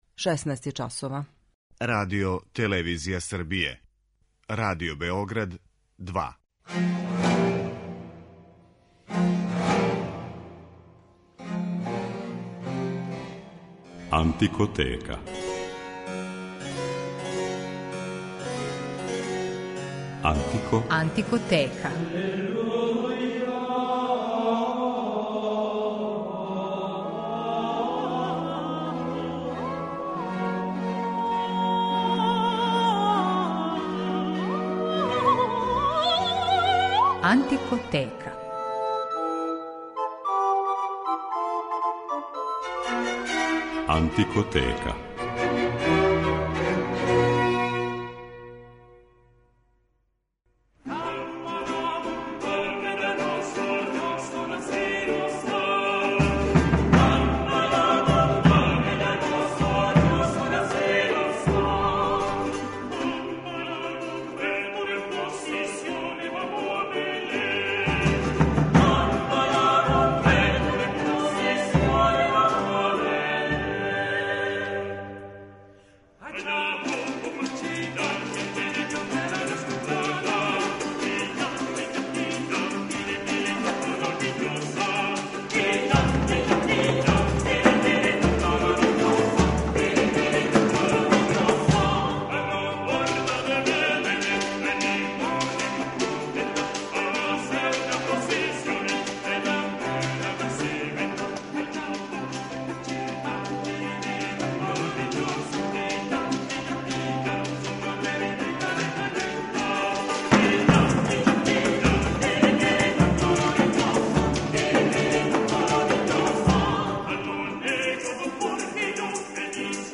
Емисија је посвећена латиноамеричкој музици 17. века
Она се стапала са музиком староседелаца (Индијанаца, али и досељеника из Африке, робова), те је добила необичан, препознатљив звук и карактер, проистекао из специфичног инструментаријума, и необичних ритмова.